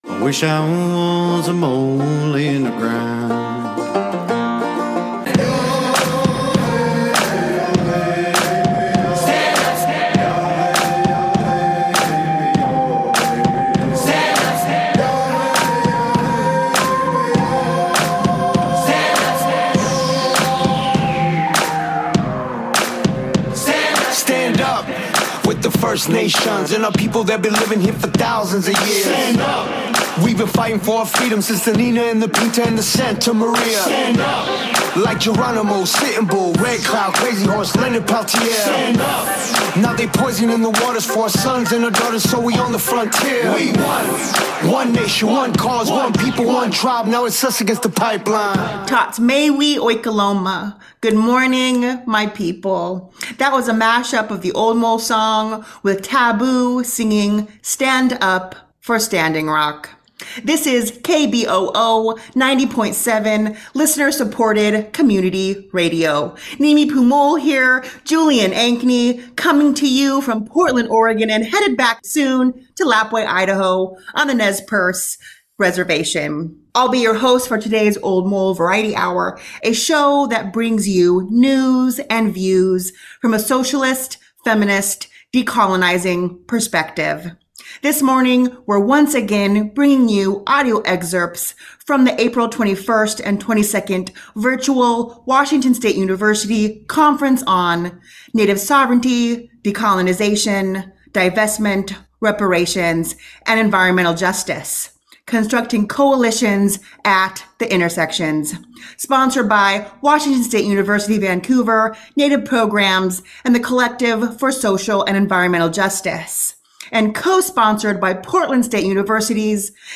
Segments from the conference include: Missing and Murdered Indigenous People, Fossil Fuels, Man Camps, and a Case for Divestment, Reinvestment, and Reparations : The sexual exploitation, torture and disappearance of Native Americans has deep historical roots, beginning in 1492.